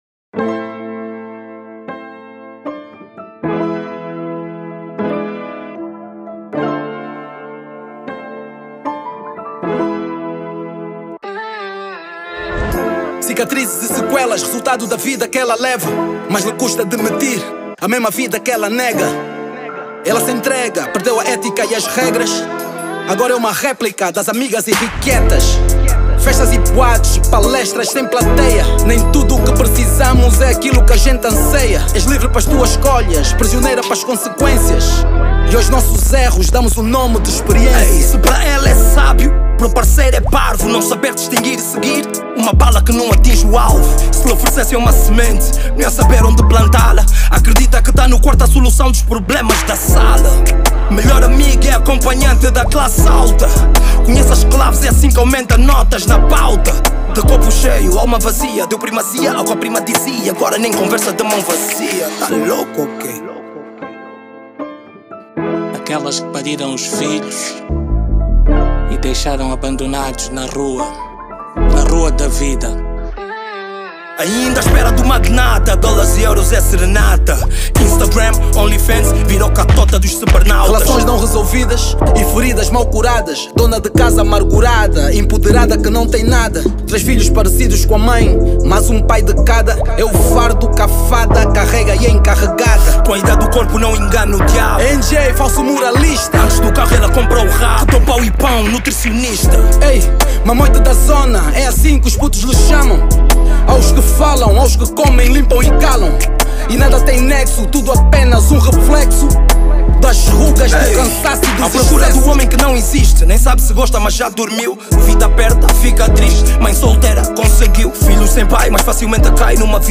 Hip-Hop/Rap Ano de Lançamento